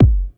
• Natural Kick Drum One Shot G Key 739.wav
Royality free steel kick drum tuned to the G note. Loudest frequency: 153Hz
natural-kick-drum-one-shot-g-key-739-C7v.wav